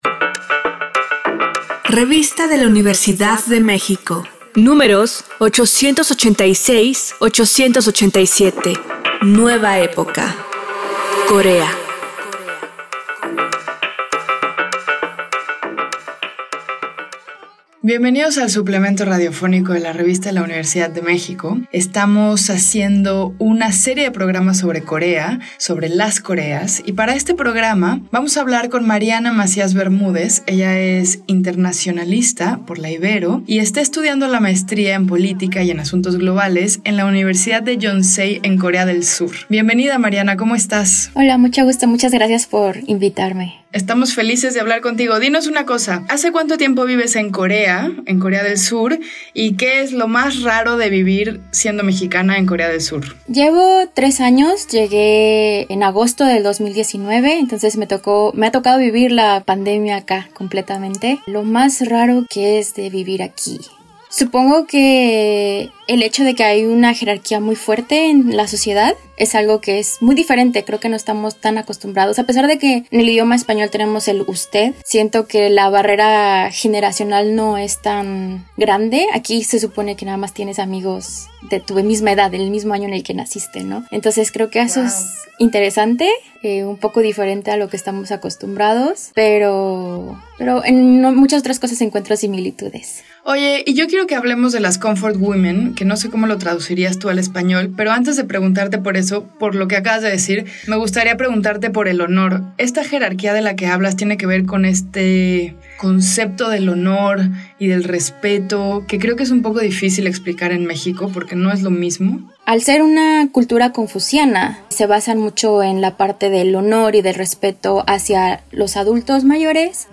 Cargar audio Este programa es una coproducción de la Revista de la Universidad de México y Radio UNAM. Fue transmitido el jueves 25 de agosto de 2022 por el 96.1 FM.